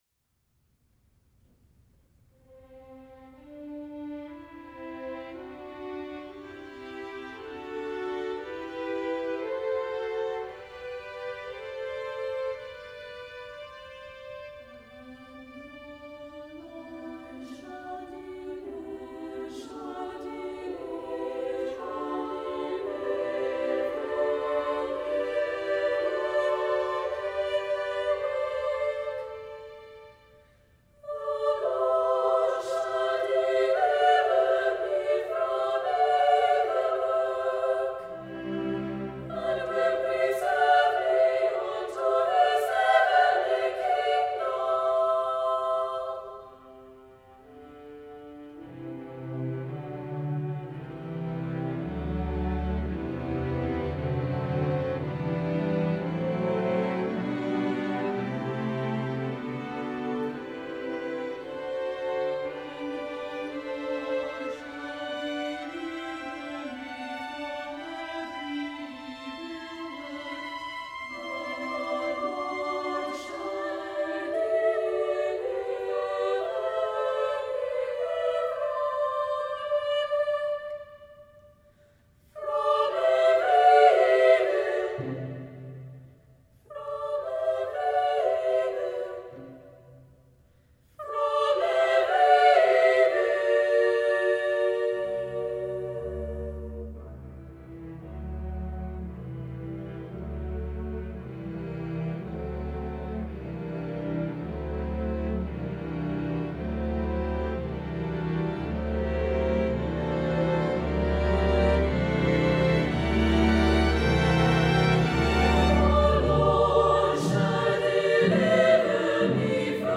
Anthem for SSA Chorus and Strings